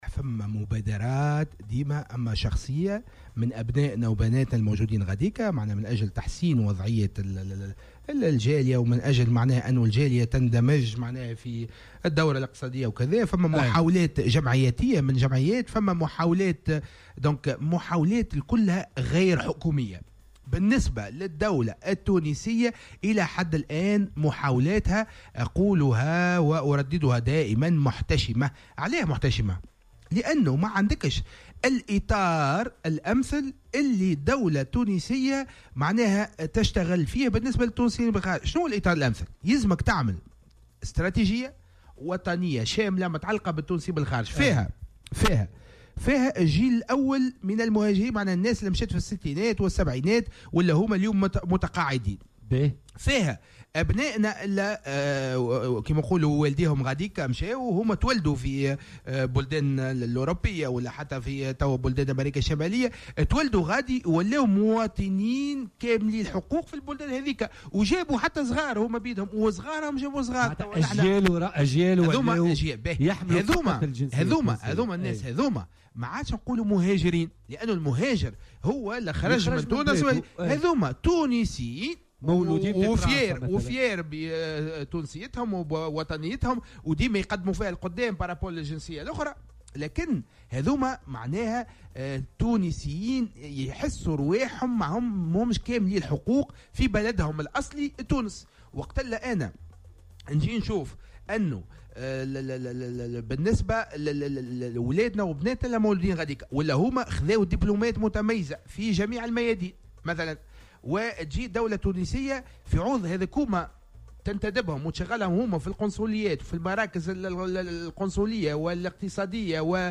وأوضح في مداخلة له اليوم في برنامج "بوليتيكا" على هامش أشغال الجامعة الصيفية الرابعة للتونسيين بالخارج، أن أغلب المحاولات هي فردية و جمعياتية و ليست حكومة، وفق تعبيره.